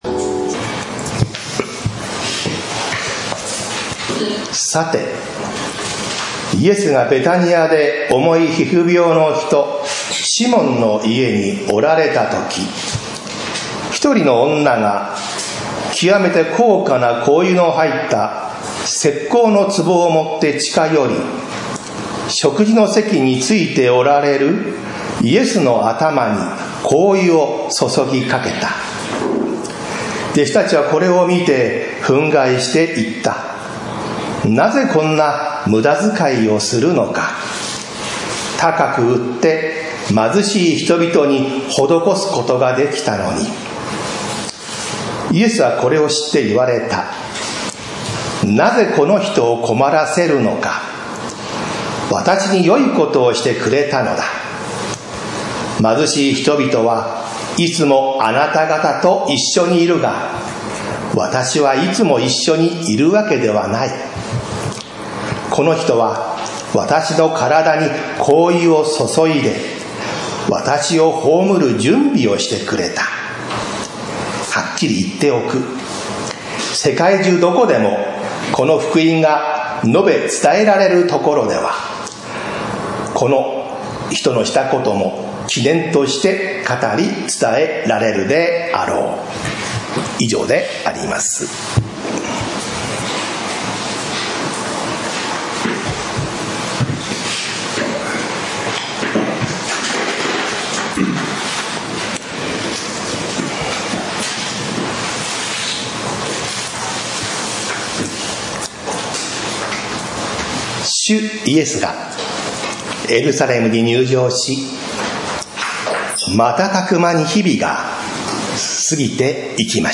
何ものにもまさる行為 宇都宮教会 礼拝説教